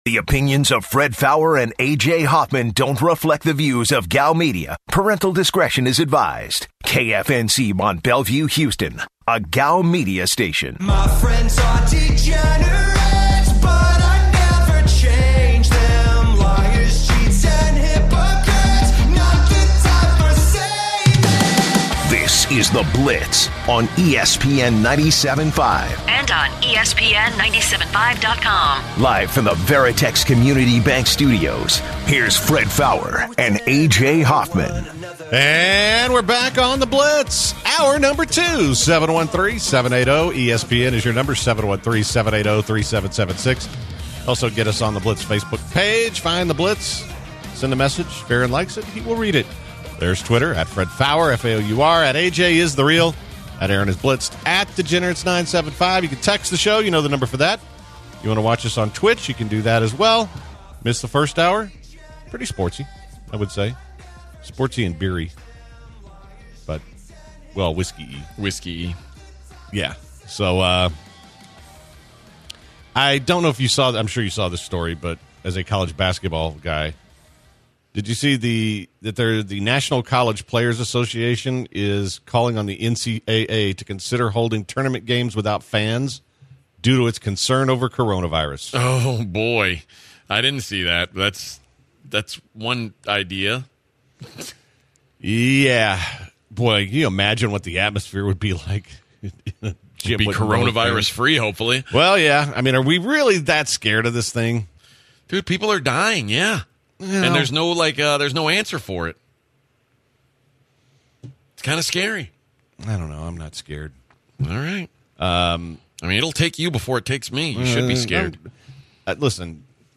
joining the show live in studio to break down the draft and everything going on at the NFL combine. Last up this hour is a phenomenal gem of the day about staying home from work when sick.